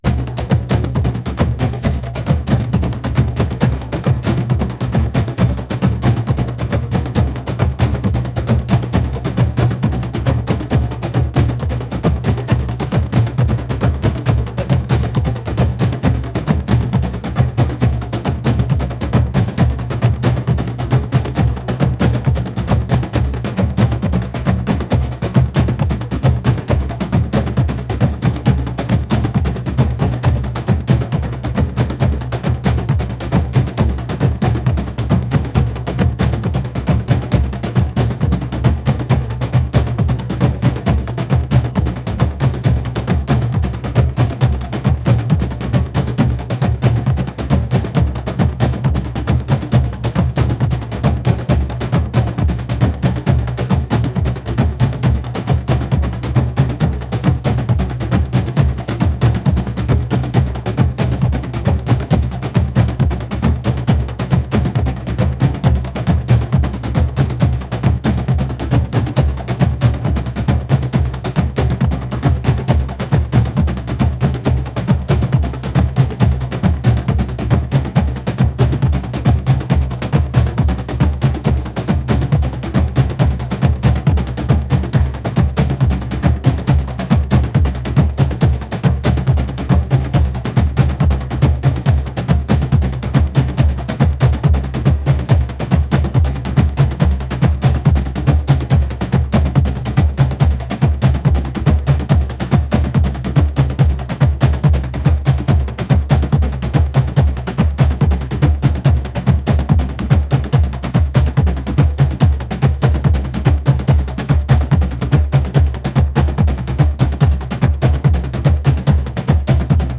world | energetic